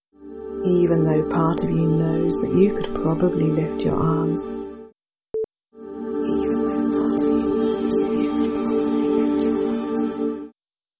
Harsh 'T' sounds... and S too I think
Making a low bitrate stereo mp3 version can add a pseudo-stereo effect, particularly on sibilance.